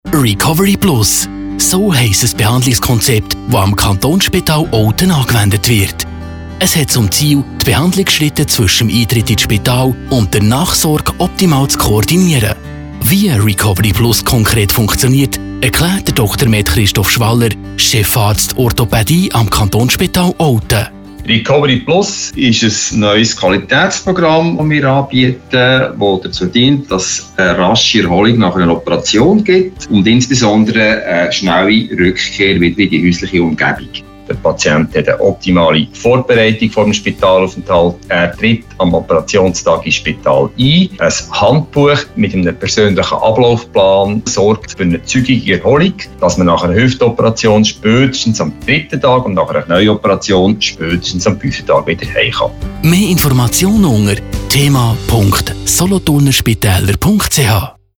Infomercial Week